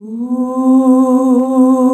UUUUH   C.wav